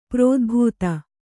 ♪ prōdhbūta